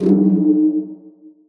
Destroy - BlackPerc.wav